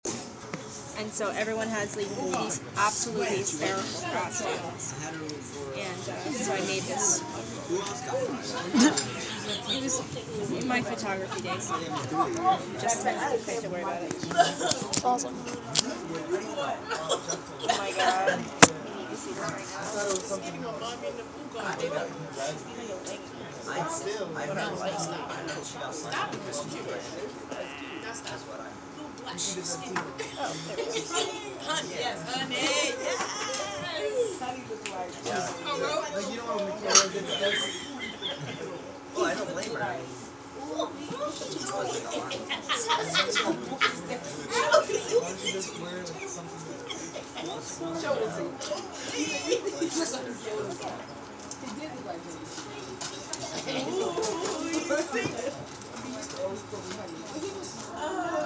resturant – Hofstra Drama 20 – Sound for the Theatre
Sounds: Talking close and at a distance, cutlery.